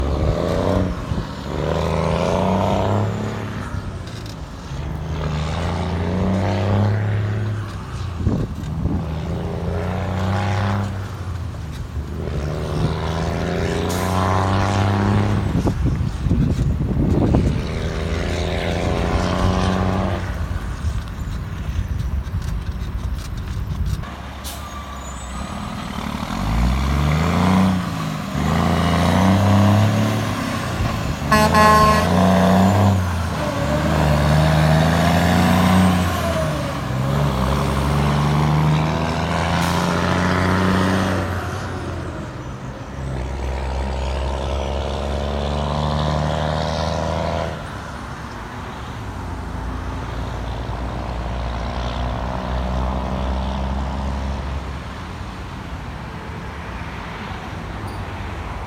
Straight Piped Sterling Water Truck